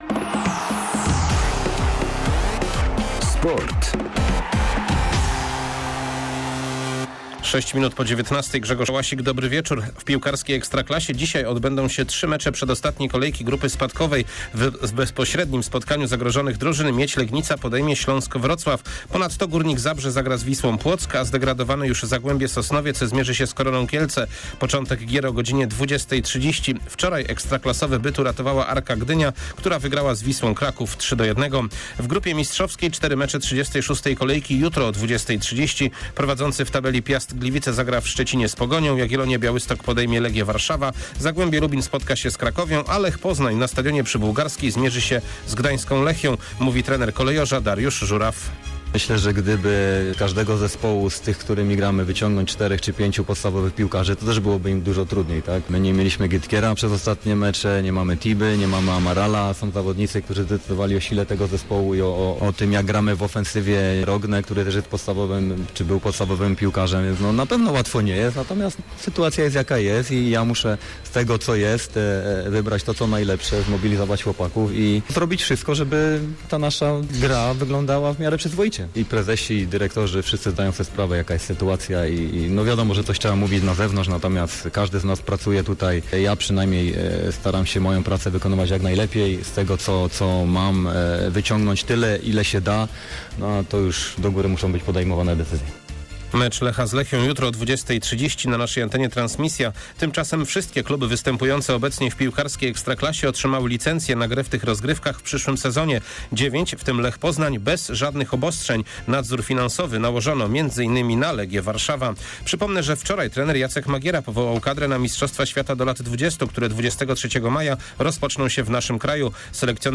14.05. serwis sportowy godz. 19:05